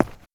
Footstep_HardSurface_01.wav